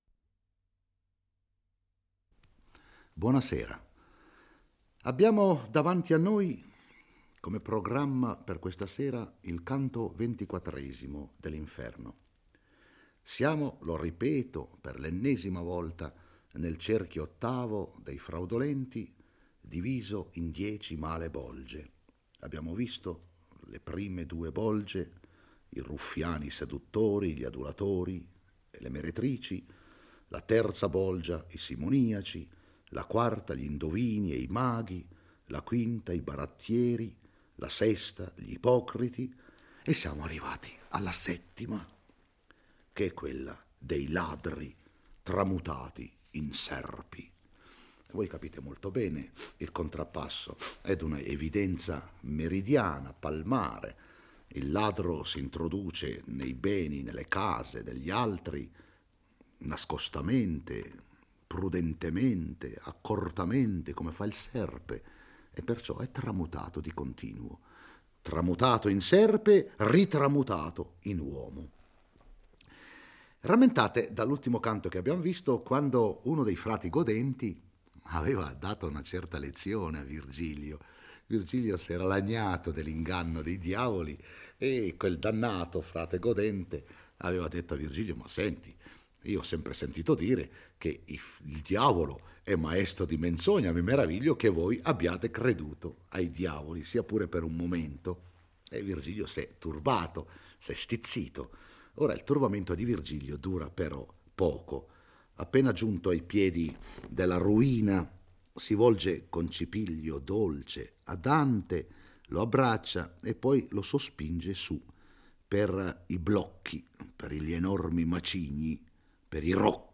legge e commenta il XXIV canto dell'Inferno. Dante e Virgilio giungono alla settima bolgia dell'ottavo cerchio, dedicata ai ladri: per il poeta sono peccatori che hanno trasgredito il settimo comandamento divino di non rubare.